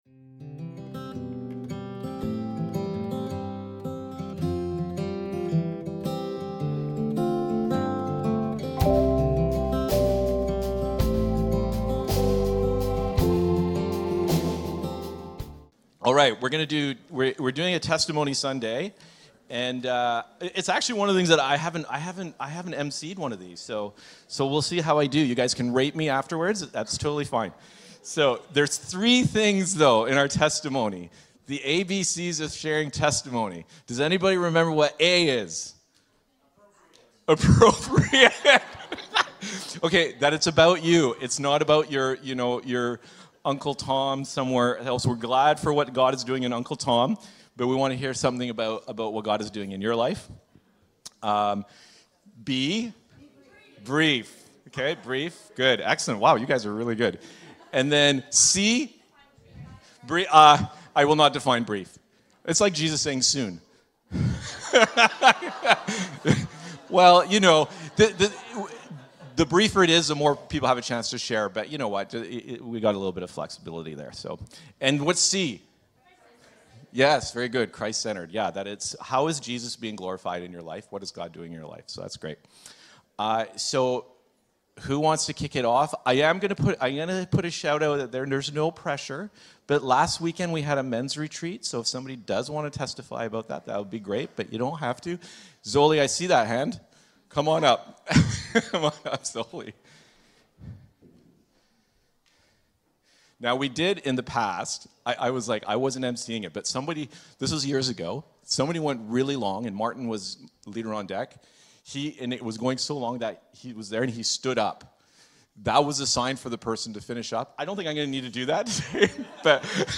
TESTIMONIES
God is working in our lives and sharing our testimonies can help encourage those around us. These regular Testimony Sundays give us the chance to celebrate how God is shaping us.